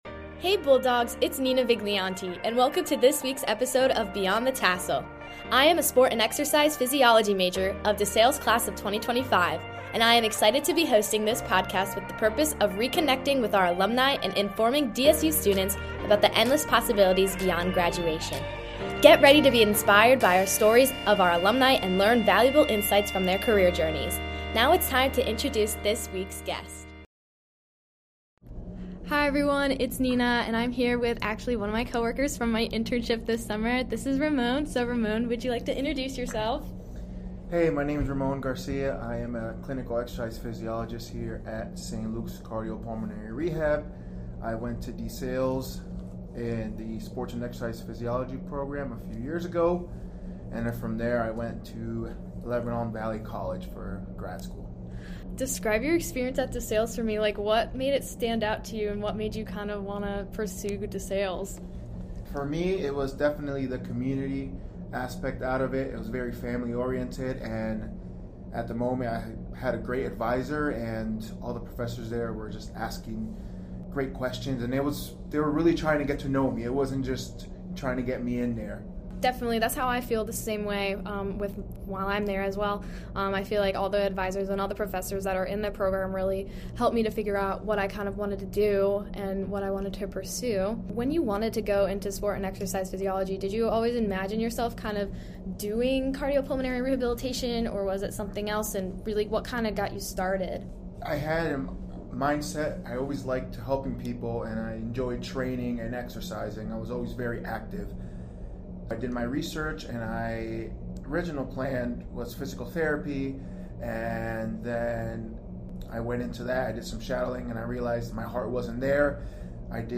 Play Rate Listened List Bookmark Get this podcast via API From The Podcast Beyond the Tassel reconnects with DeSales University alumni to inspire and inform current students about the infinite opportunities that our graduates have experienced beyond college. Through informational interviews, this podcast will share stories, experiences, insights and resources to help current DeSales University students explore real world opportunities and spark further career curiosity.